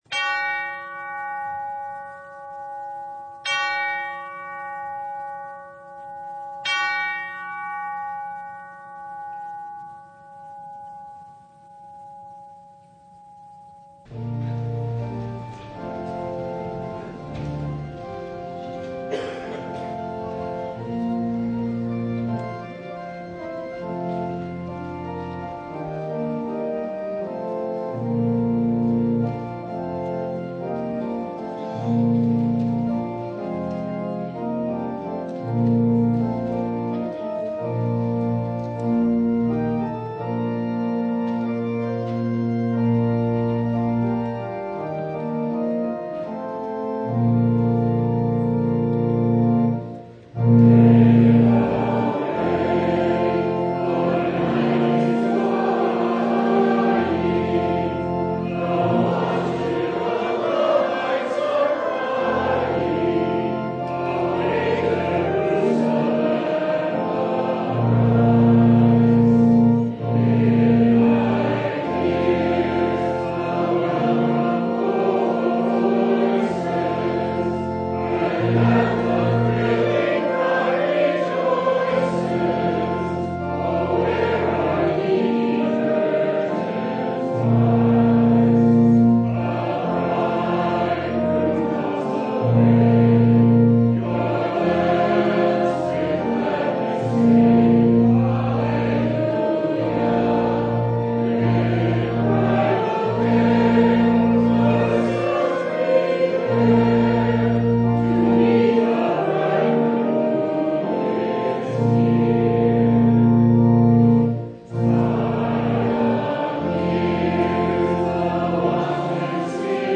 Matthew 22:1-14 Service Type: Sunday Guess who’s been invited to the wedding feast for the King’s Son?